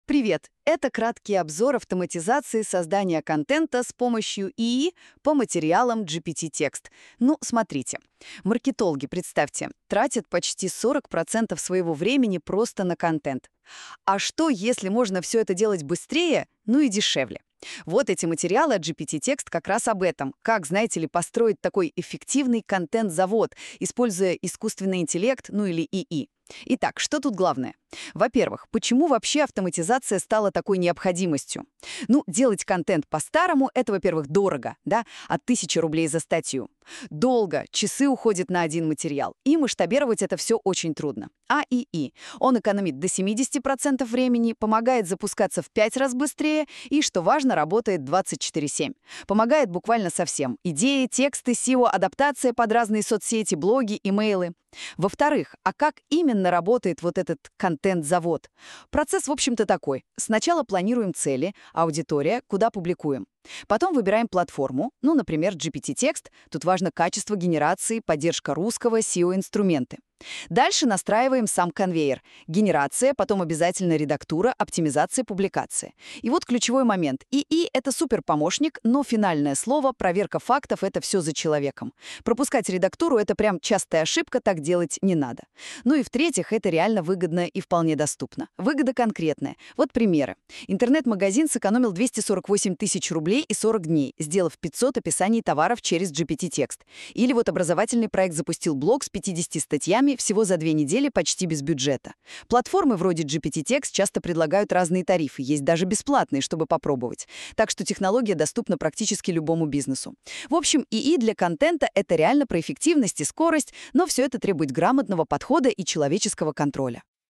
avtomatizaciya-kontenta-audio-pereskaz.mp3